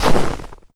STEPS Snow, Run 14.wav